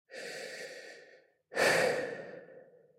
lowOxygen.wav